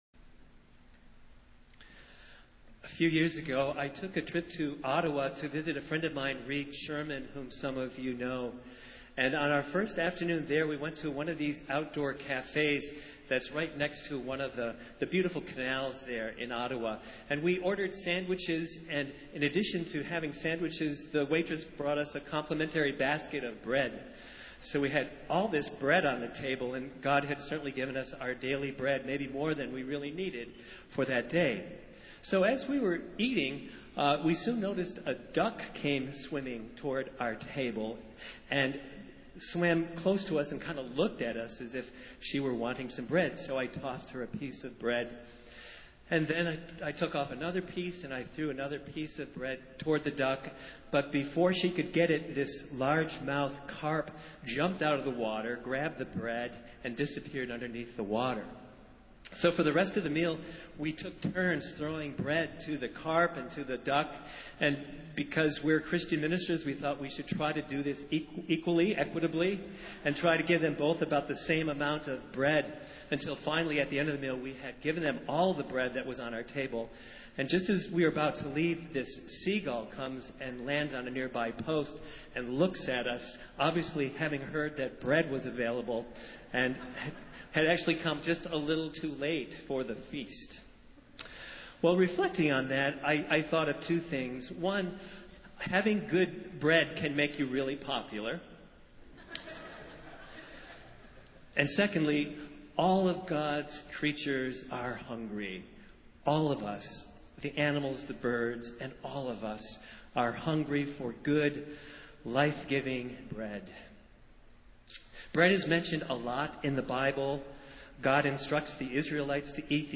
Festival Worship - Eleventh Sunday after Pentecost